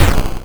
ihob/Assets/Extensions/explosionsoundslite/sounds/bakuhatu75.wav at master
bakuhatu75.wav